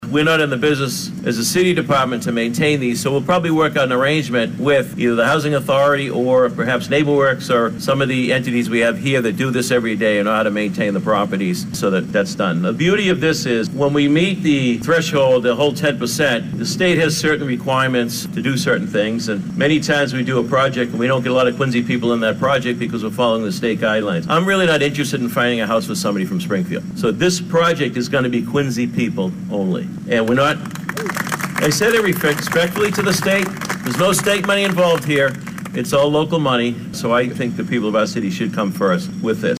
Mayor Thomas Koch echoed that sentiment saying it will enable the city to achieve two goals.